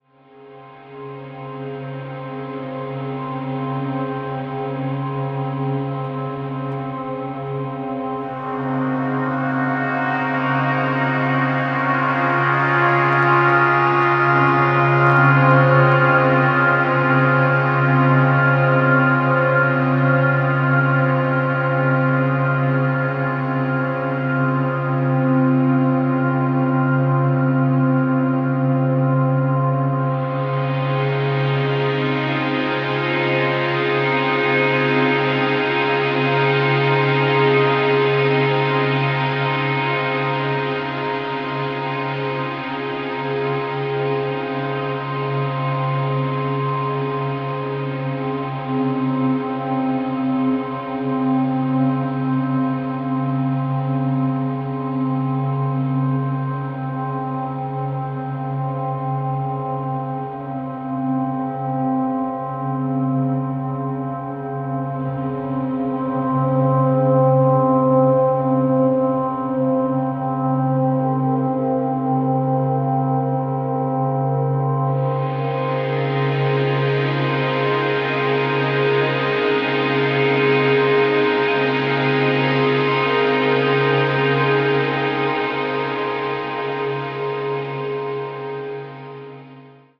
豊かに反復するドローン、細やかなピアノの残響、丹念に作り上げられた音像と１曲１曲がドラマチックに満ちてゆく。